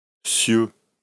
Cieux (French pronunciation: [sjø]